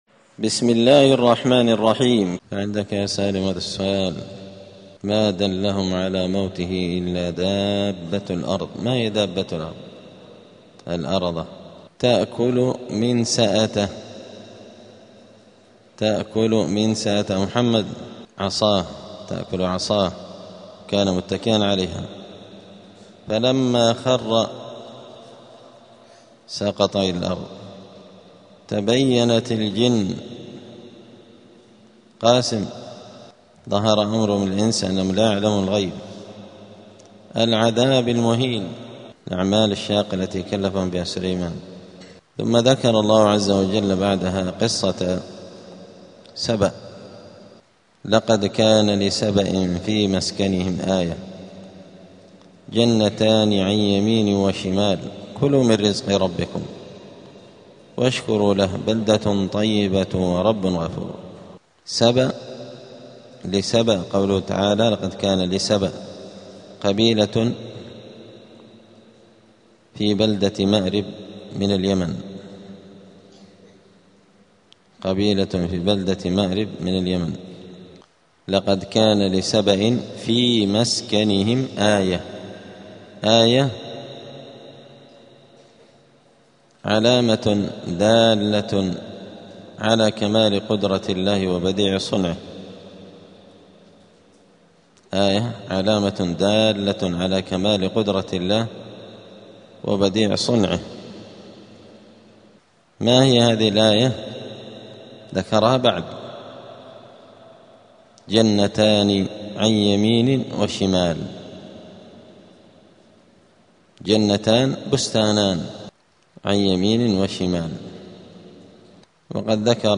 الأربعاء 2 شعبان 1447 هــــ | الدروس، دروس القران وعلومة، زبدة الأقوال في غريب كلام المتعال | شارك بتعليقك | 6 المشاهدات